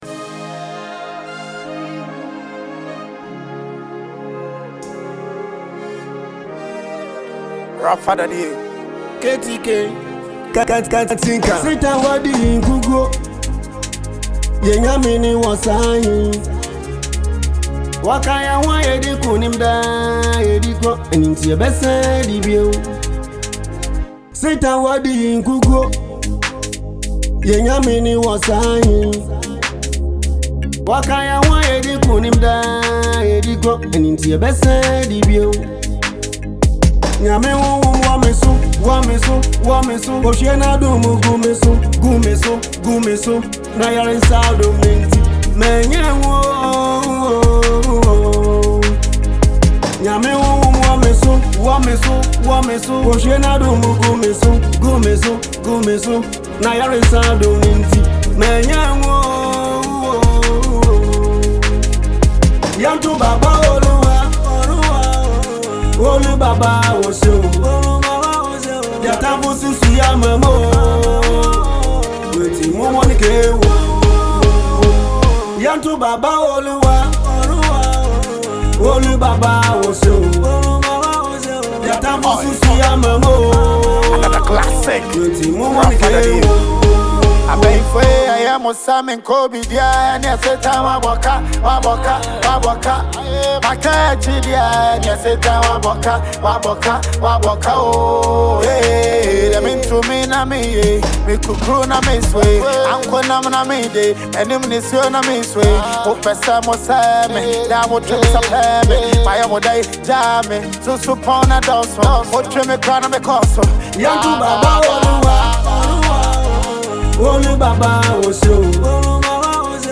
a Ghanaian singer new into the music scene